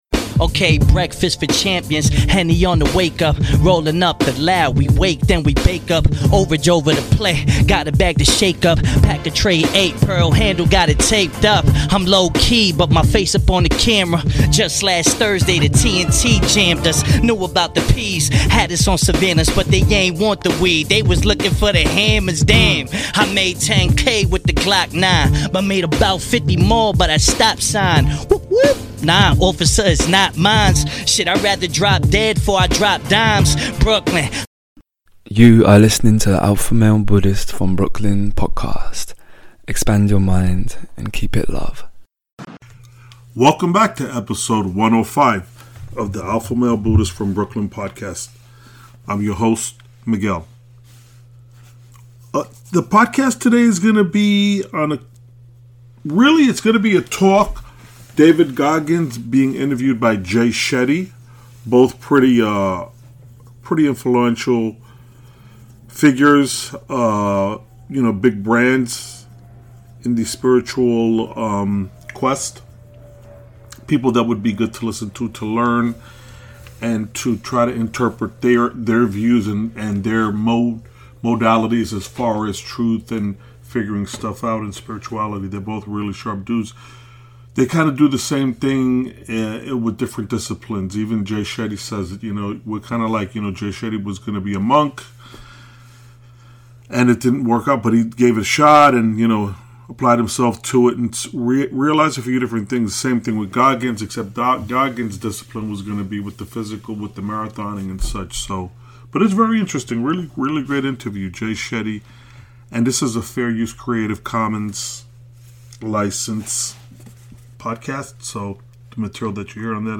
EP 105 - David Goggins interviewed by Jay Shetty - Be the Master Of your Mind - Carpe Diem